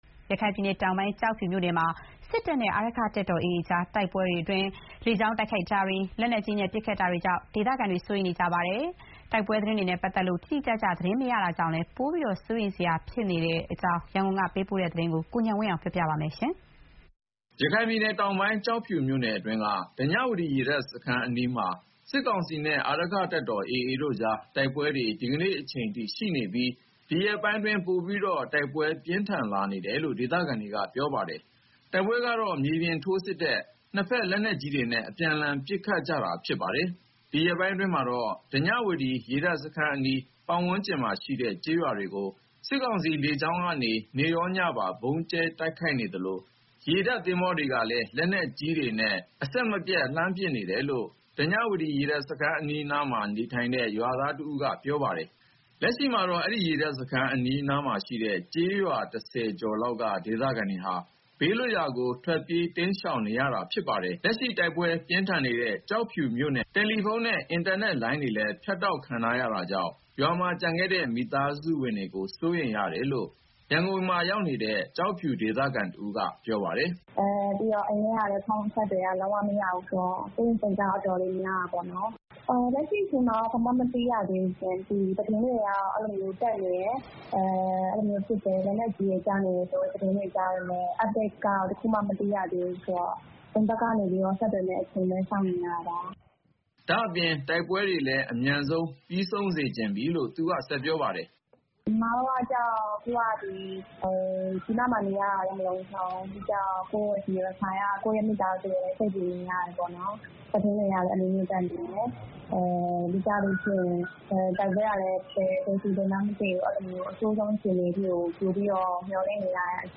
ရခိုင်ပြည်နယ် တောင်ပိုင်း ကျောက်ဖြူမြို့နယ်မှာ စစ်တပ်နဲ့ အာရက္ခတပ်တော် AA တို့ကြား တိုက်ပွဲမှာ လေကြောင်း တိုက်ခိုက်မှုတွေ၊ လက်နက်ကြီးနဲ့ ပတ်ခတ်မှုတွေကြောင့် ဒေသခံတွေ စိုးရိမ်နေကြပါတယ်။ တိုက်ပွဲသတင်းတွေနဲ့ပတ်သက်လို့ တိတိကျကျ သတင်း မသိရတာကြောင့်လည်း ပိုပြီး စိုးရိမ်စရာဖြစ်နေကြတဲ့ အခြေအနေအကြောင်း ရန်ကုန်က ပေးပို့တဲ့သတင်းဖြစ်ပါတယ်။